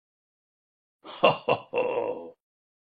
Talking Ben Saying Ho Ho Ho Sound Effect Free Download
Talking Ben Saying Ho Ho Ho